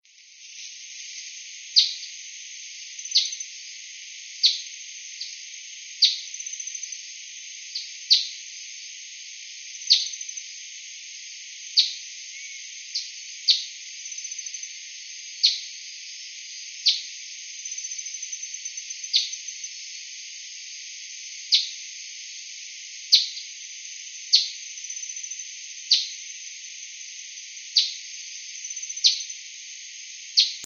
Ruby-crowned Tanager (Tachyphonus coronatus)
Life Stage: Adult
Province / Department: Misiones
Location or protected area: Bio Reserva Karadya
Condition: Wild
Certainty: Recorded vocal